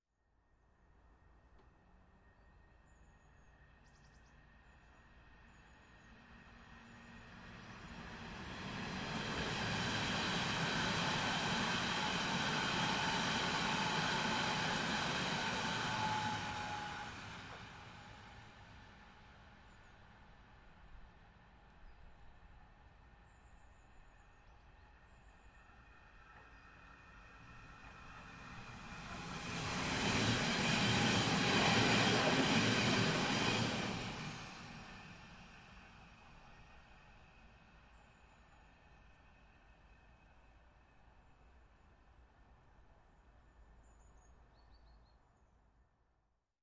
在德国柏林Priesterweg经过的列车 " S Bahn城市列车经过的近景
描述：两辆SBahn列车：第一列是大约15米，第二列是5米。 2016年9月在柏林Priesterweg以Zoom HD2录制成90°XY
Tag: 铁路 火车 城市列车 铁路 铁路 铁路 火车 电动火车 铁路 现场记录 轻轨 乘客列车